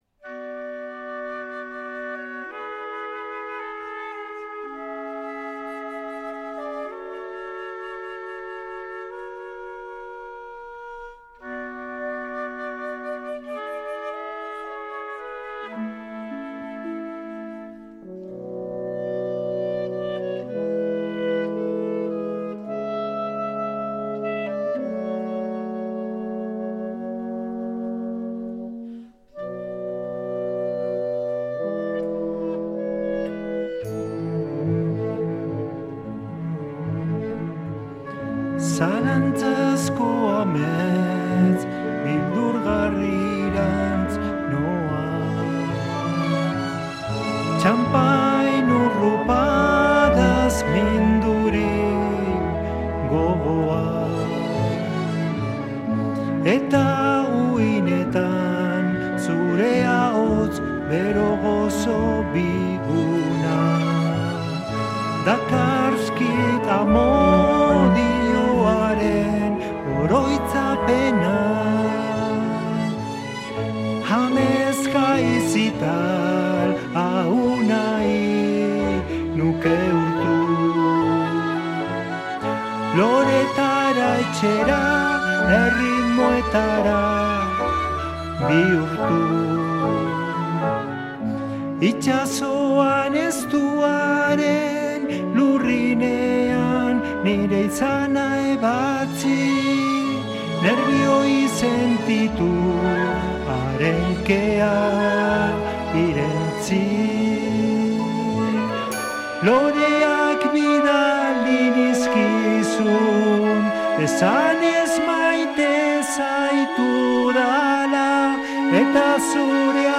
Entrevista al cantautor portugalujo por su nuevo disco